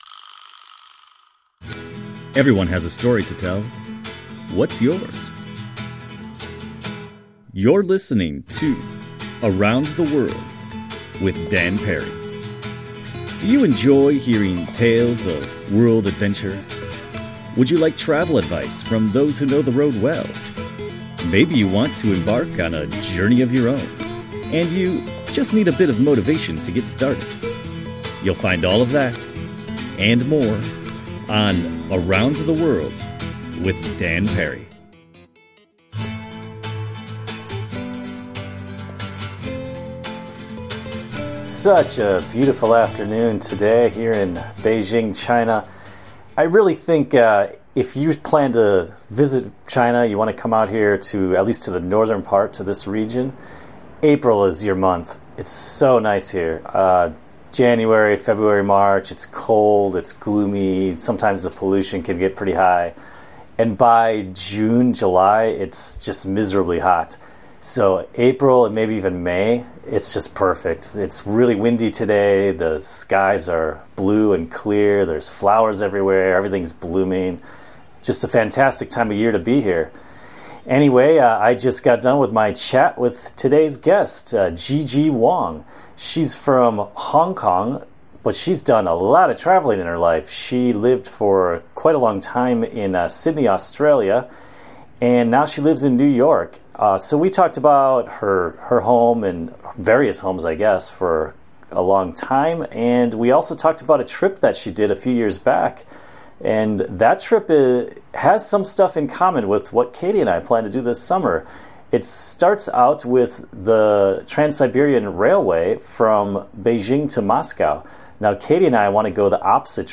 Now, let's get to our discussion: